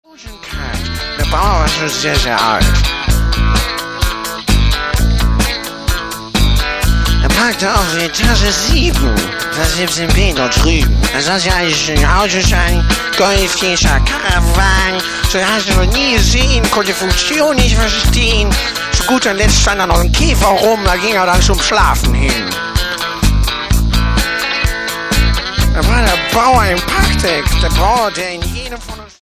Disc 1 floors it with brutal punk shorts.
Lyrics are sung entirely in German.